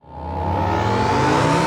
lasercharge.ogg